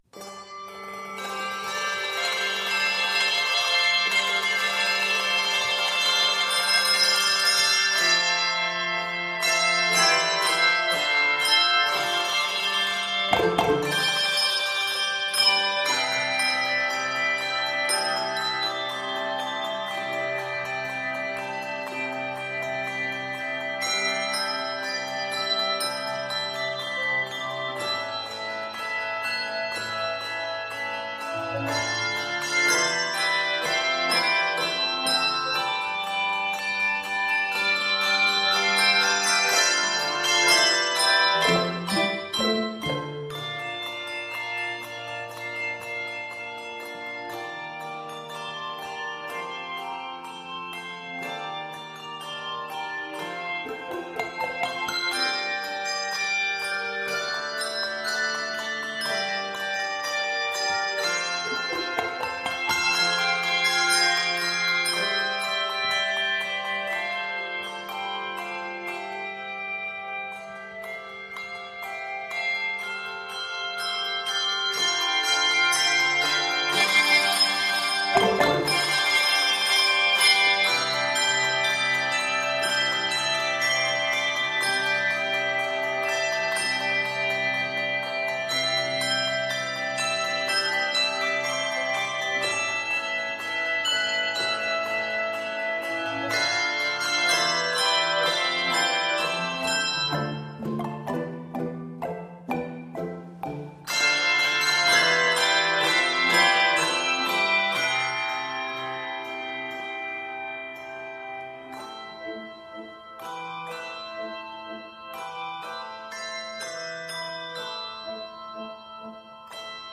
handbell setting